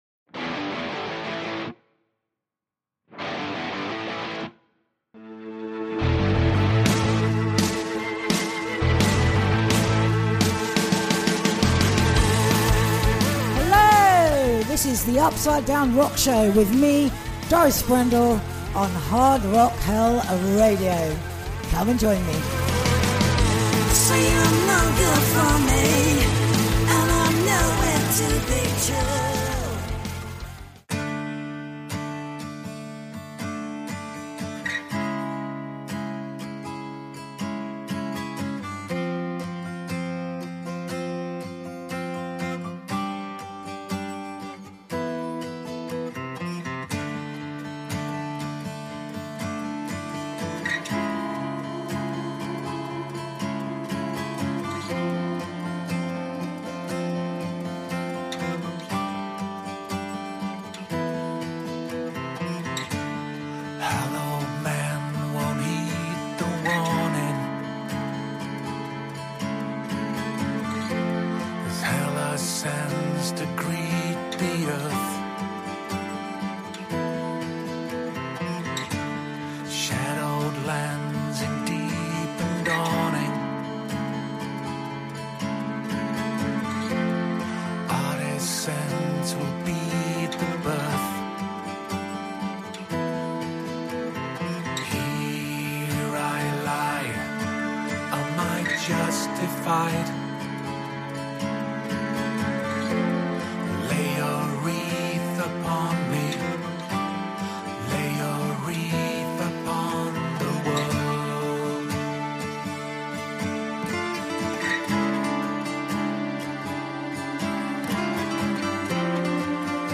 Plus a fabulous selection of new rock releases